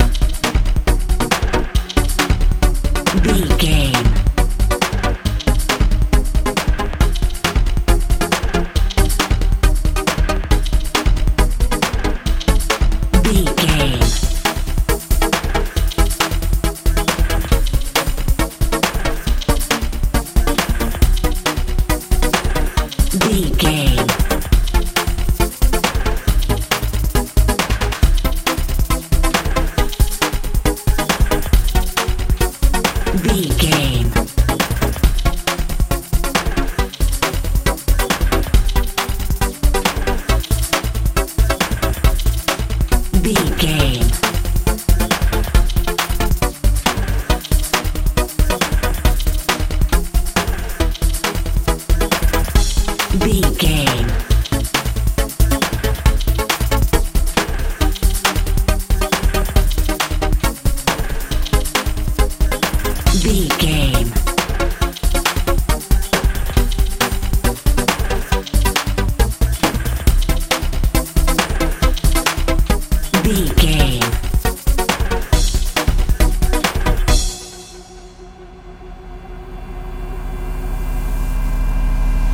kpop feel
Aeolian/Minor
bouncy
dreamy
synthesiser
bass guitar
drums
80s
90s
strange
suspense